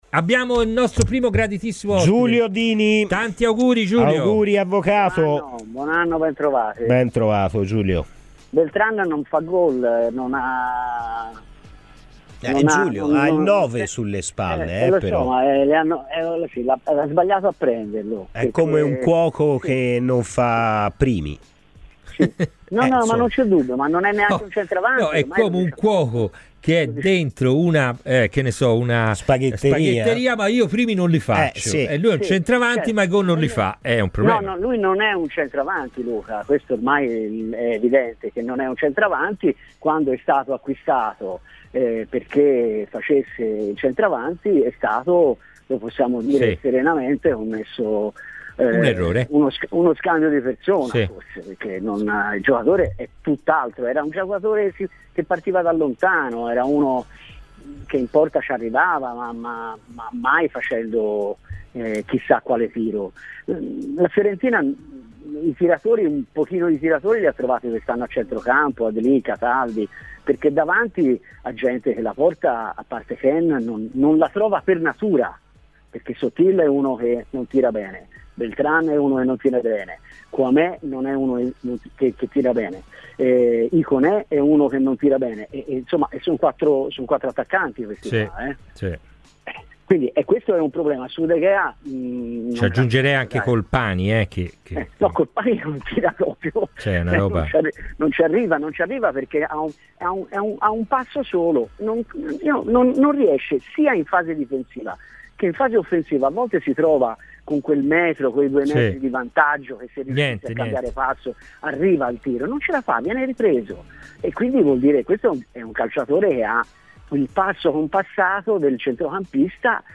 Durante Palla al Centro, trasmissione di Radio FirenzeViola